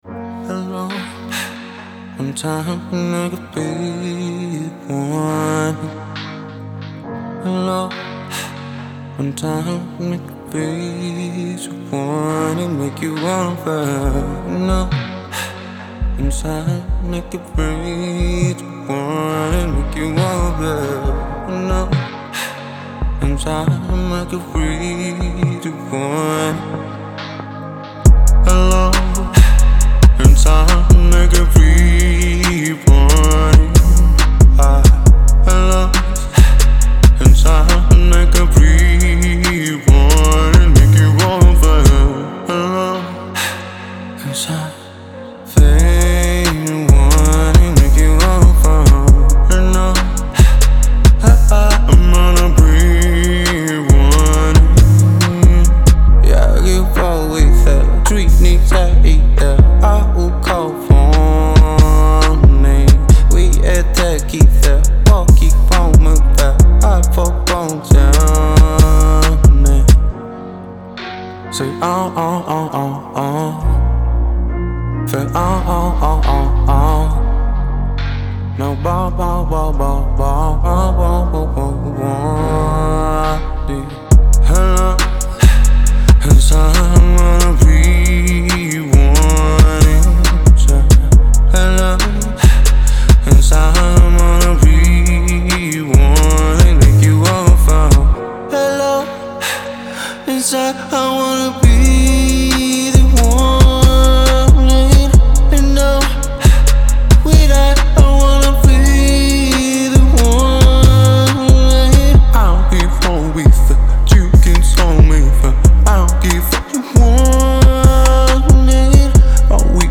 это яркая композиция в жанре поп-рэп